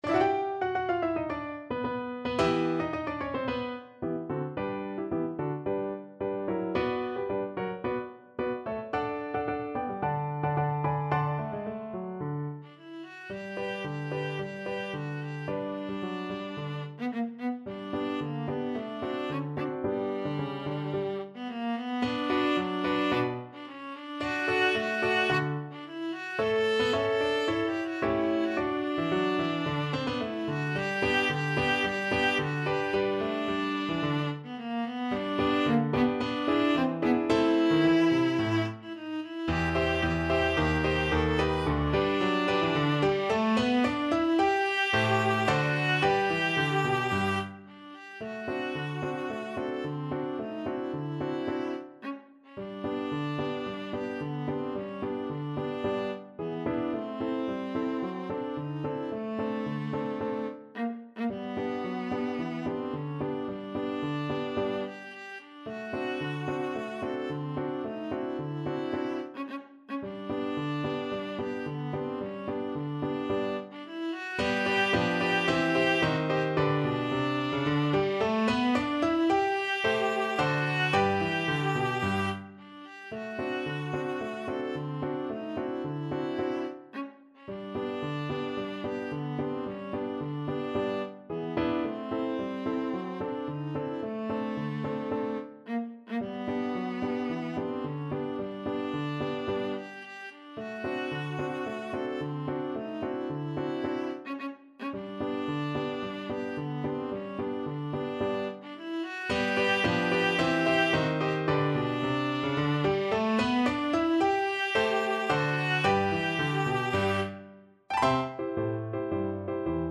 Classical (View more Classical Viola Music)